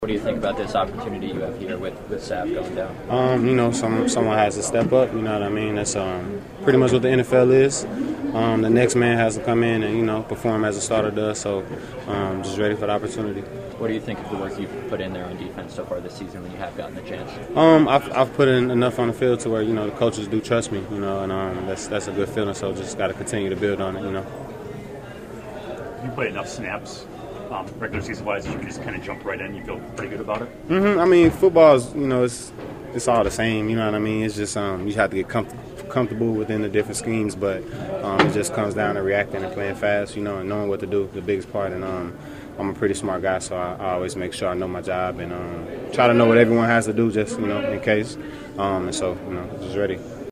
The secondary shakeup will have 5th year pro Jonathan Owens step in at safety for Savage.    Reporters caught up with him post practice.